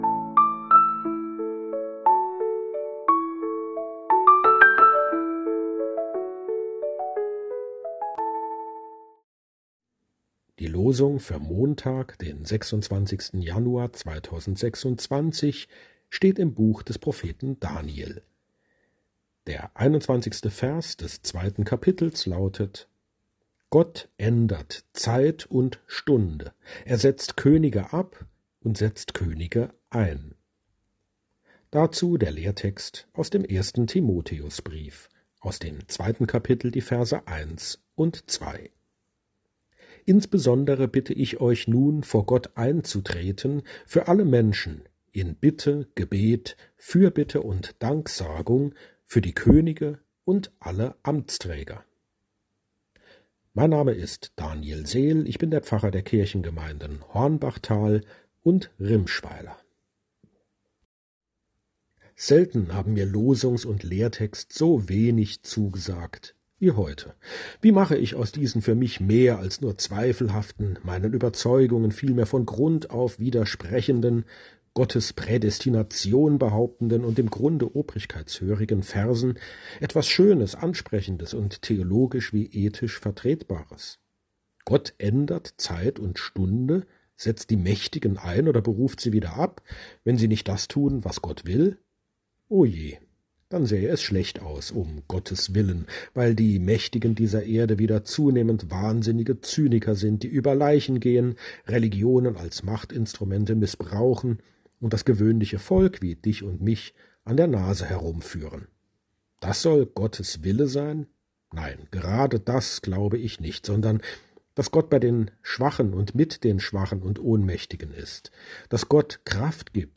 Losungsandacht für Montag, 26.01.2026